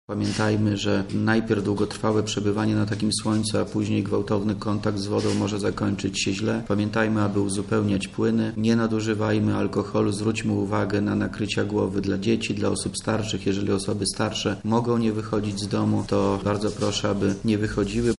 – Korzystajmy z lata bezpiecznie i rozsądnie – apeluje Wojciech Wilk, wojewoda lubelski
Briefing u wojewody1